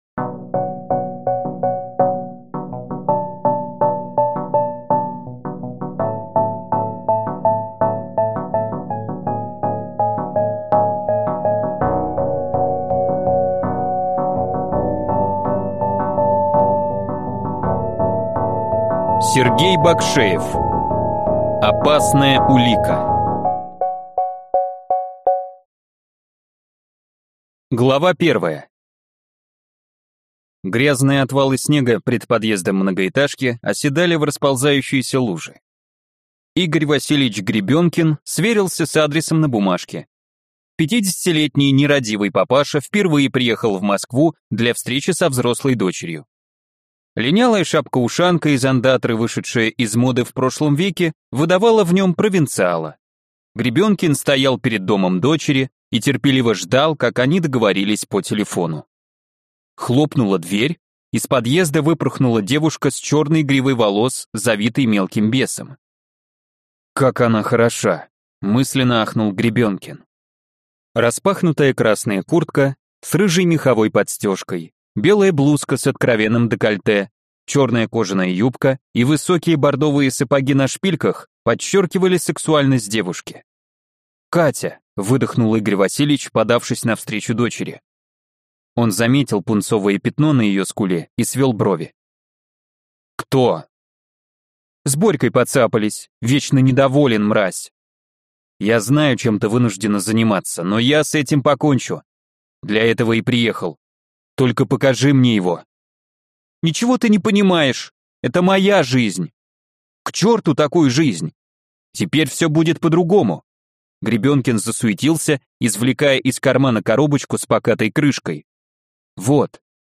Аудиокнига Опасная улика | Библиотека аудиокниг